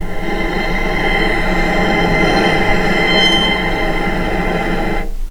vc_sp-C6-pp.AIF